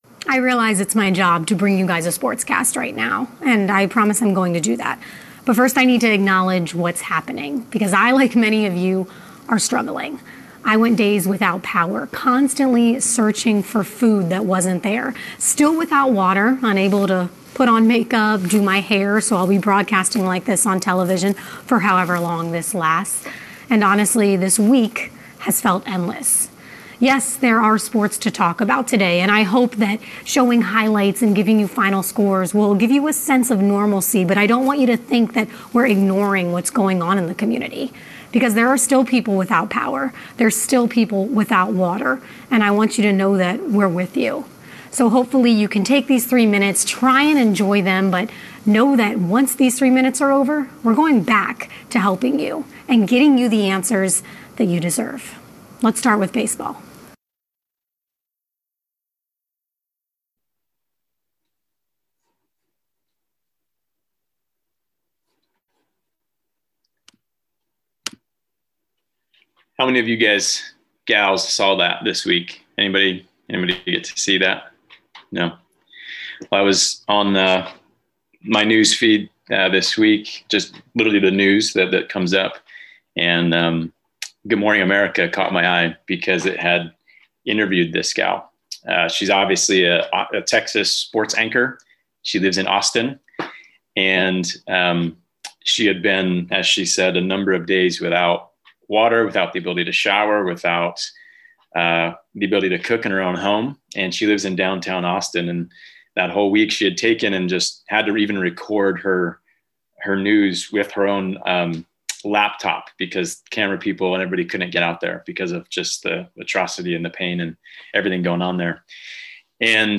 Sermons | Missio Community